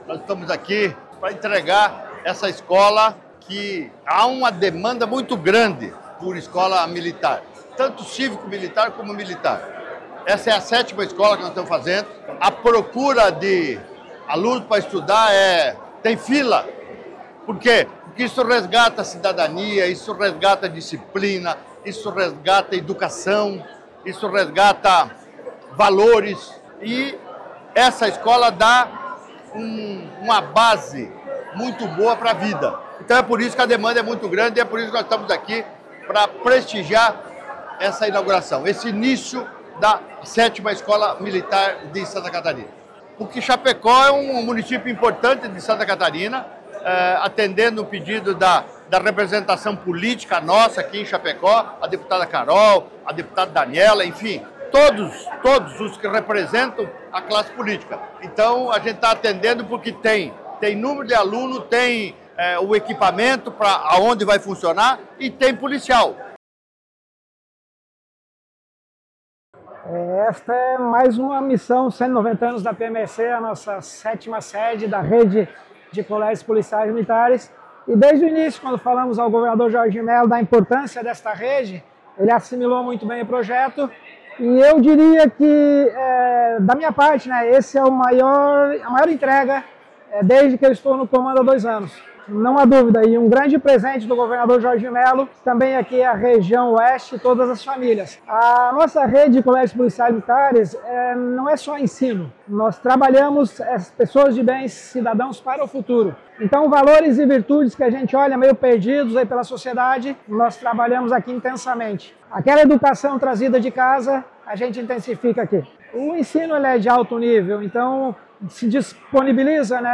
O governador Jorginho Mello ressaltou a alta demanda por escolas desse modelo:
O comandante-geral da Polícia Militar de Santa Catarina, coronel Aurélio José Pelozato, ressalta que nesse formato, não é oferecido só o ensino, mas sim, prepara-se cidadãos para o futuro: